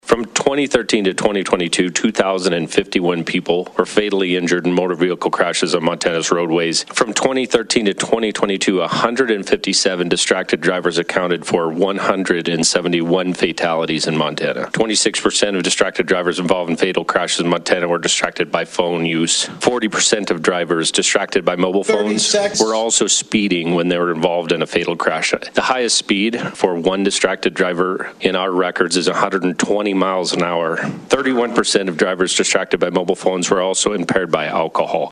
Chris Dorington, Director of the Montana Department of Transportation, spoke before the House Judiciary Committee in support of Senate Bill 359 which now moves to the House Floor for debate. On Friday Dorington gave some statistics of accidents related to distracted driving.